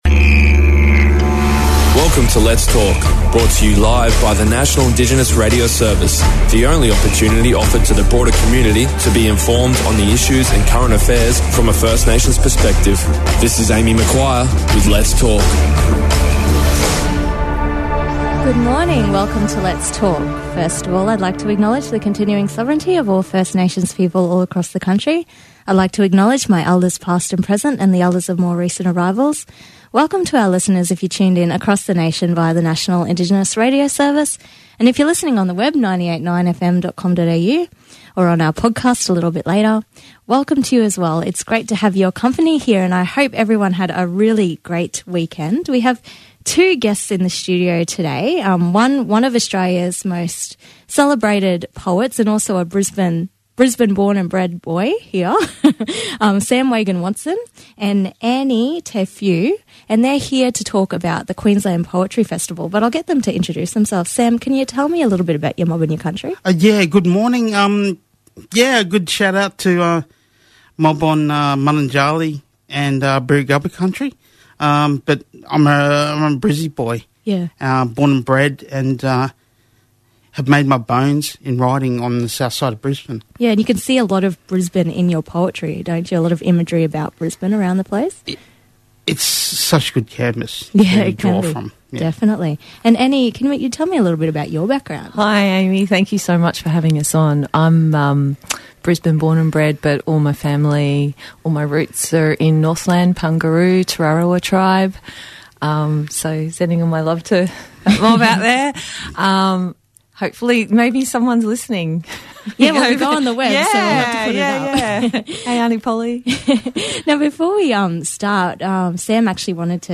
He also reads out his poem ‘Let’s Talk’, dedicated to Tiga Bayles.